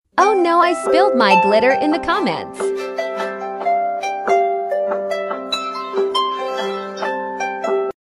Hehe Sound Effects Free Download